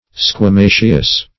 (skw[.a]*m[=a]"sh[u^]s)